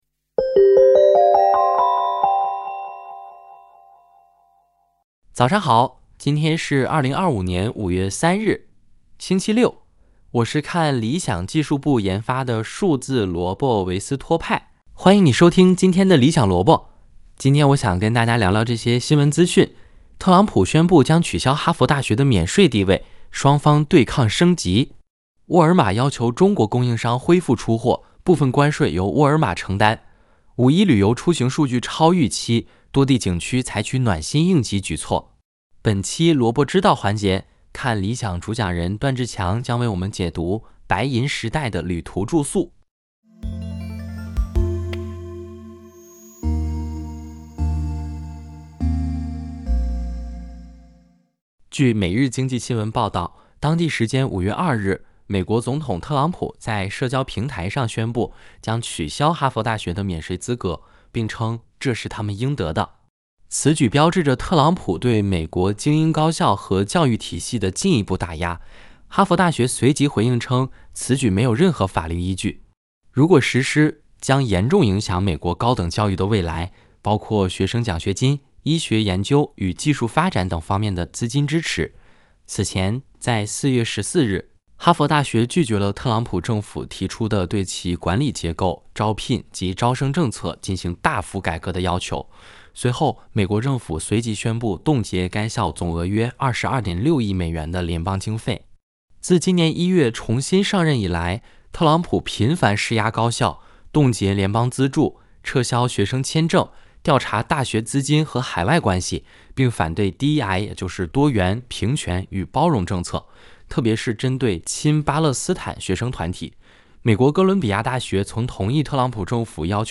《理想萝卜》是由看理想技术部研发的数字萝卜维斯托派主持的资讯节目。